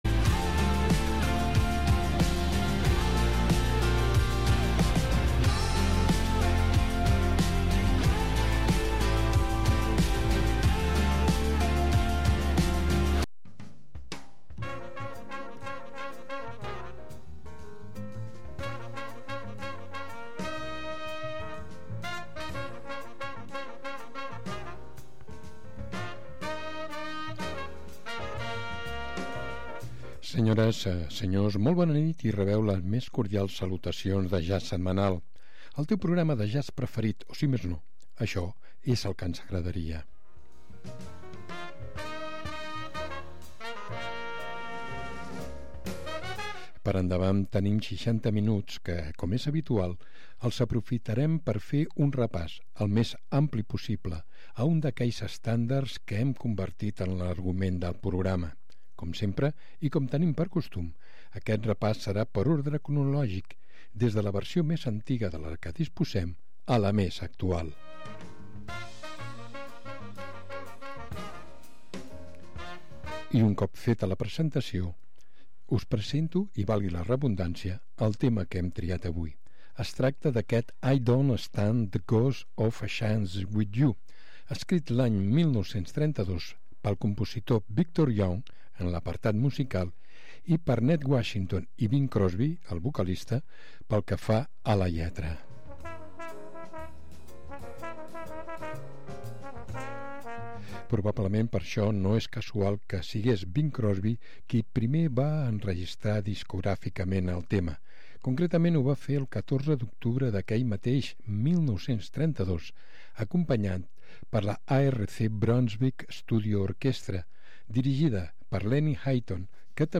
Jazz Setmanal